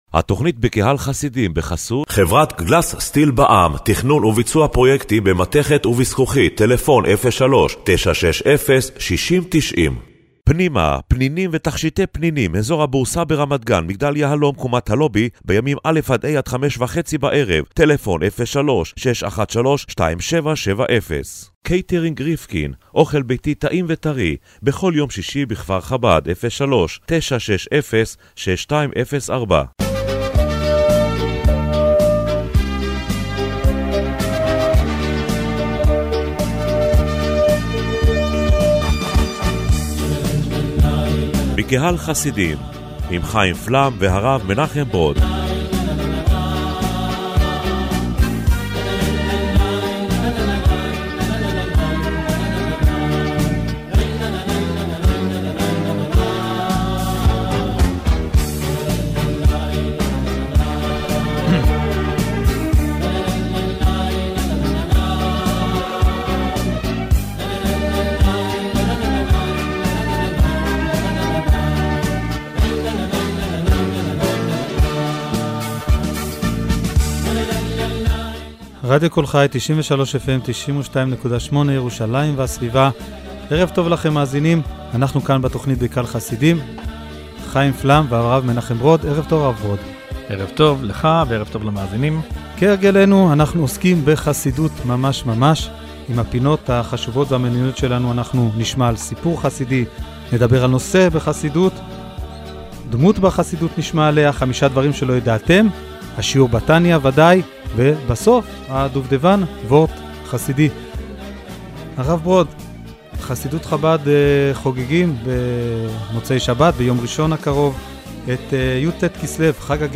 תכנית הרדיו השבועית בקהל חסידים השבוע עמדה בסימן חג הגאולה יט כסלו המתקרב ● למה אמר החסיד למתנגד כי עליו לשמוח בחג הזה יותר...